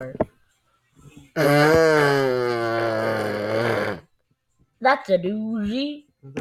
Lint Licker (Orbit Gum) Voicerecord Sound Effect - Sound Buttons Universe